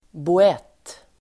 Ladda ner uttalet
Uttal: [bo'et:]